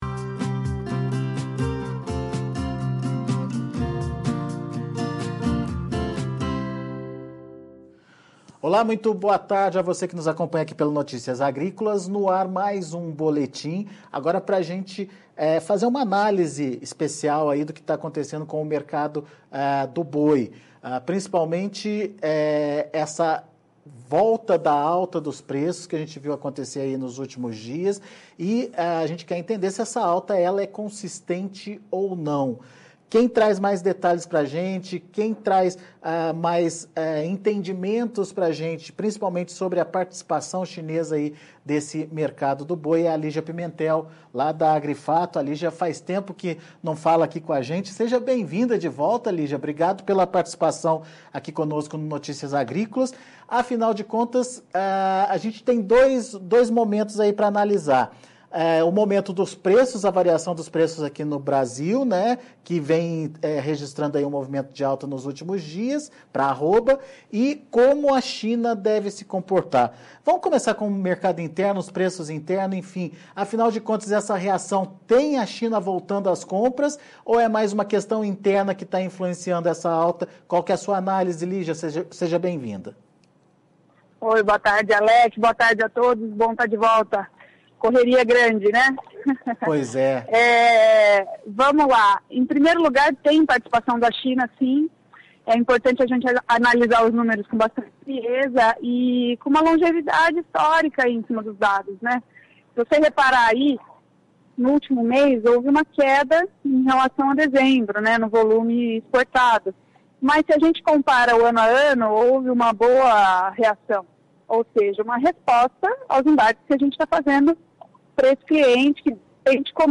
Mercado do boi – Entrevista